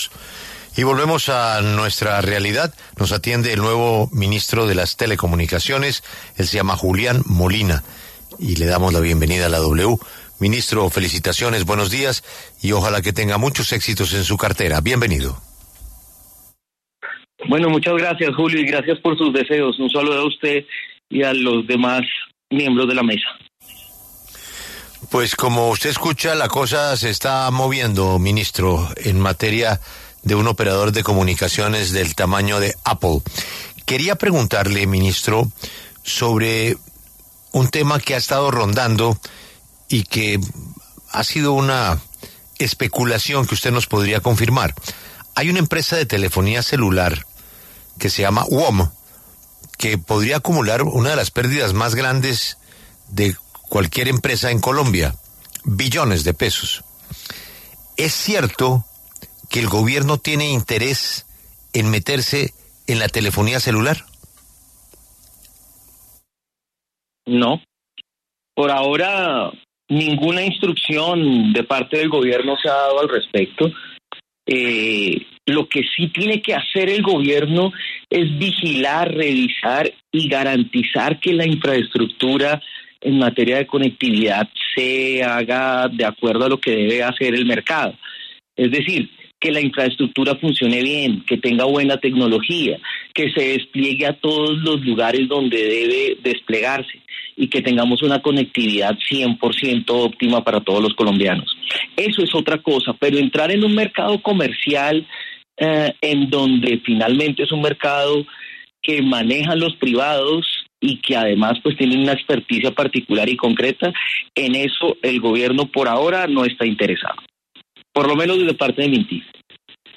Julián Molina, ministro de las TIC, conversó con La W para aclarar el rumor sobre el supuesto interés del Gobierno de entrar en el mercado de la telecomunicación.